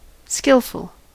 Ääntäminen
IPA: /gə.ˈʃɪkt/